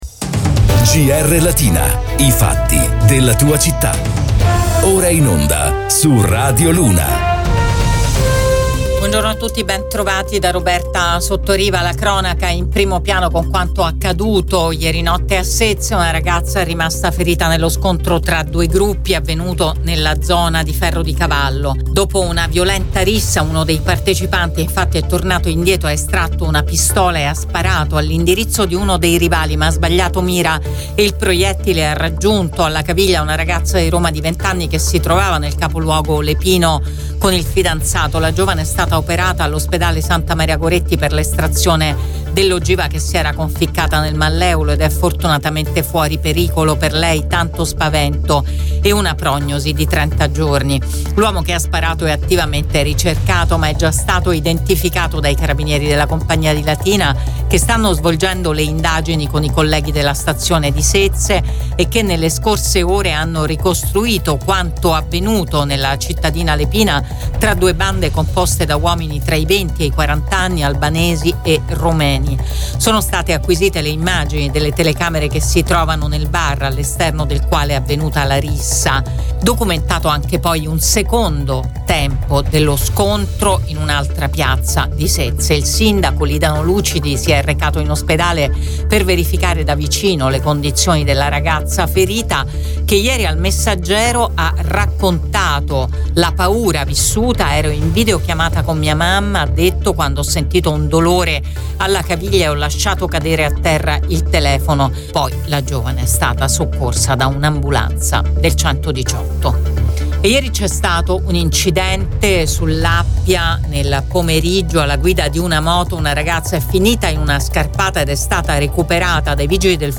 LATINA – Qui puoi ascoltare il podcast di GR Latina in onda su Radio Immagine, Radio Latina e Radio Luna